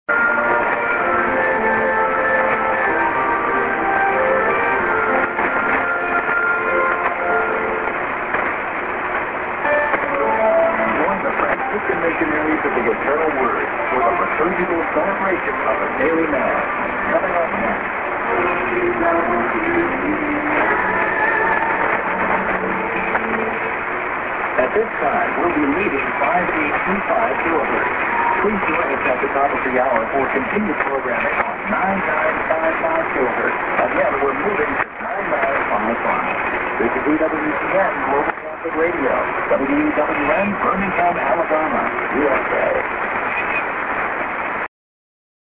->ANN(man:ID+SKJ,EWTN+WEWN)->s/off